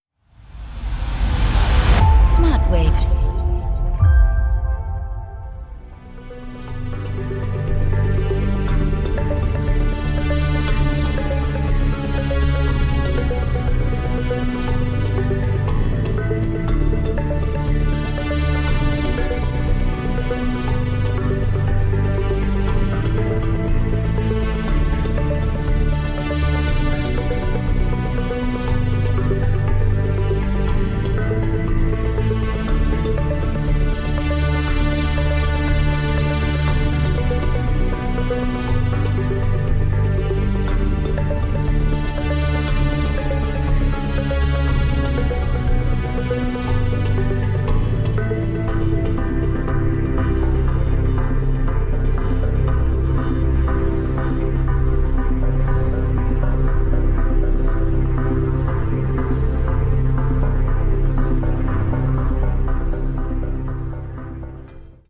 Binaurale & Isochrone Beats